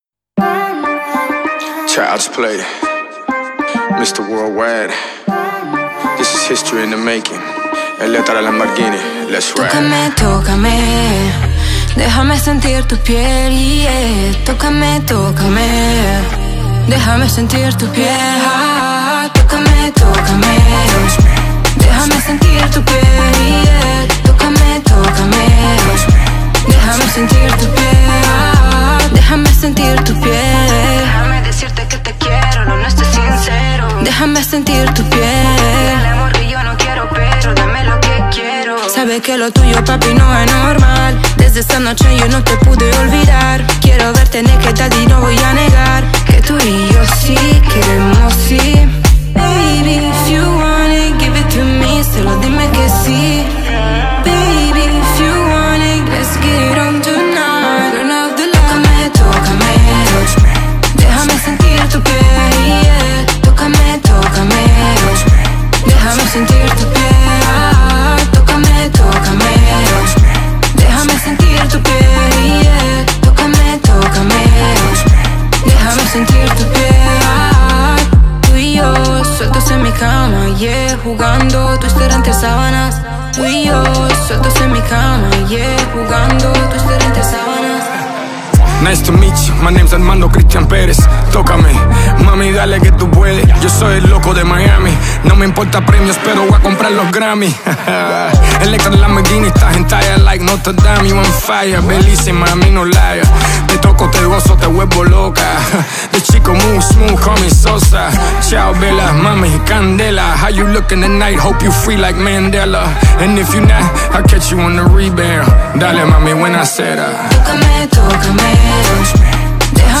это энергичный трек в жанре латинского попа и реггетона